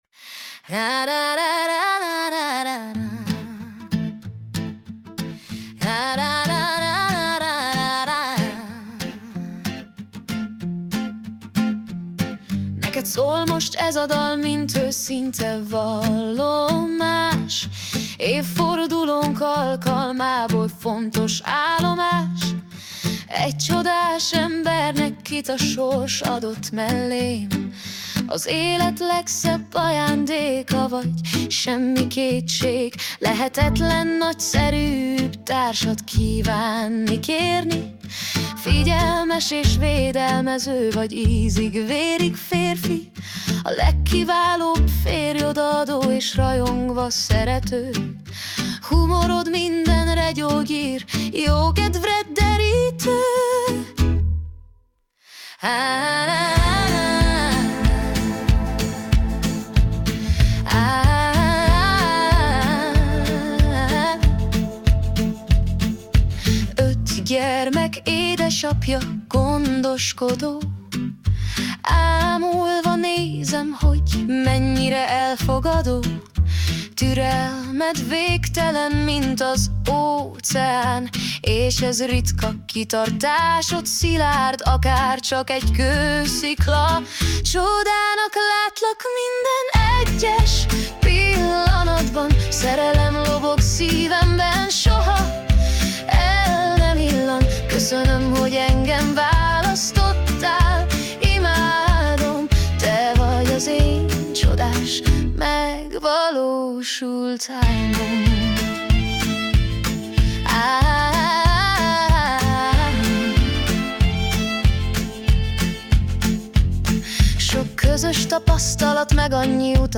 Romantikus ajándék dal